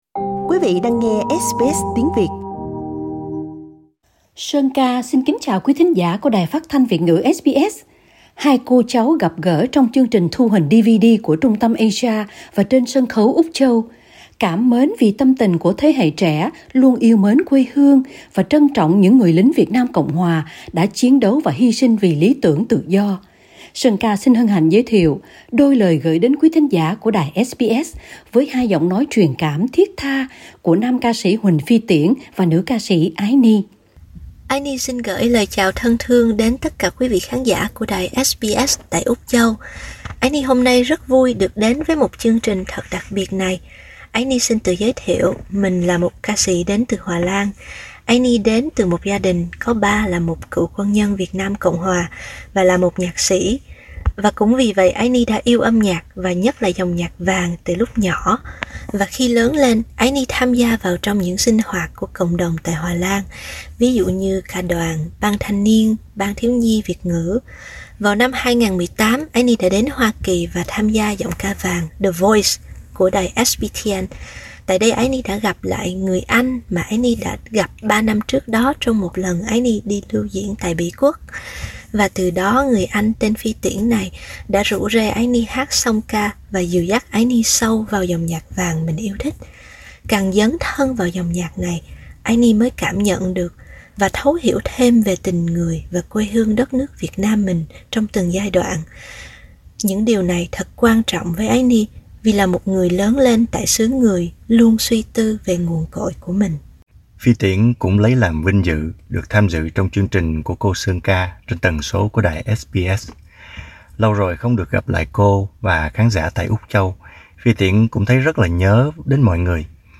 hai giọng ca truyền cảm thiết tha